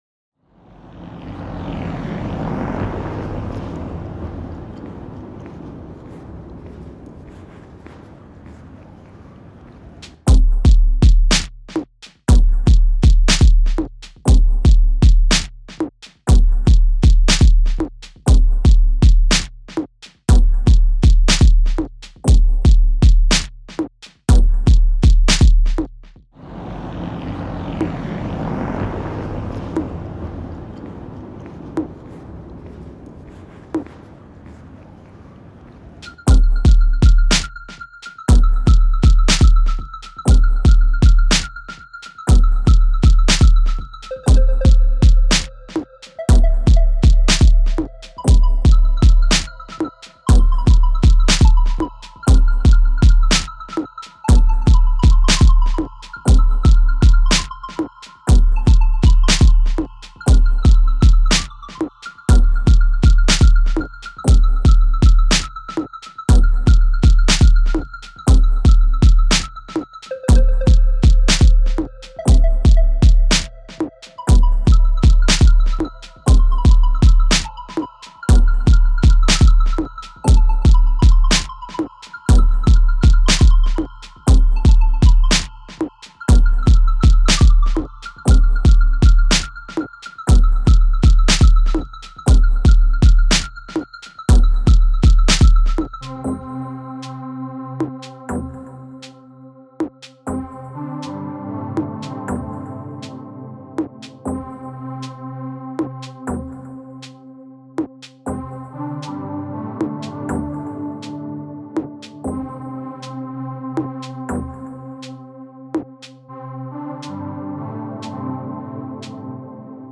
KopfsteinpflasterUndAmEndeVoegelAmSee.mp3